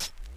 True Colours Hi-Hat.wav